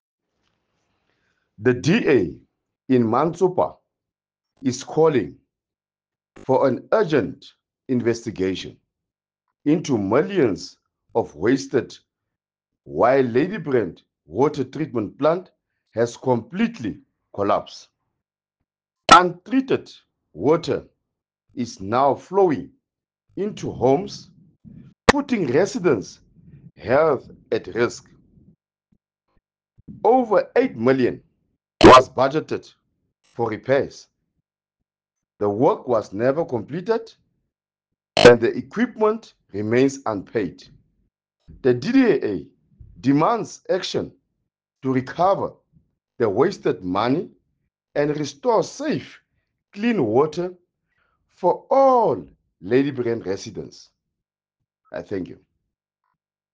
Issued by Cllr. Nicky van Wyk – DA Councillor Mantsopa Municipality
English, Afrikaans and Sesotho soundbites by Cllr Nicky van Wyk.